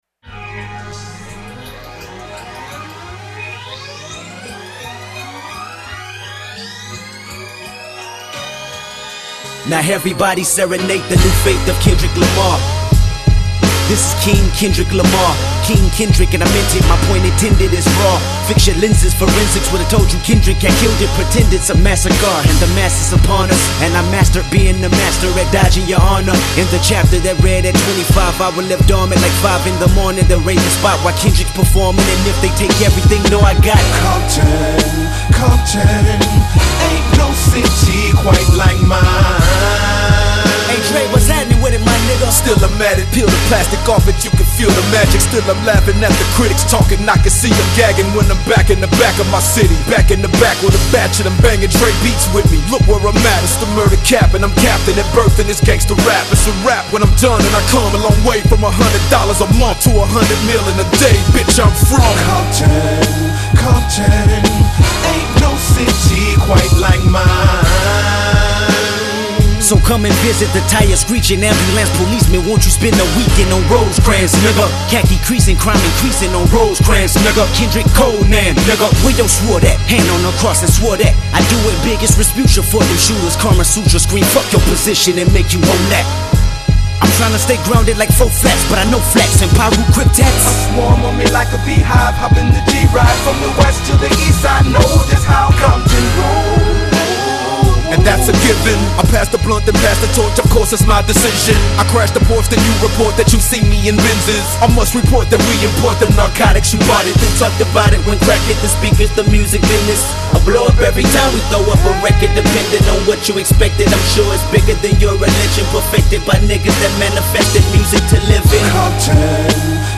bassline thump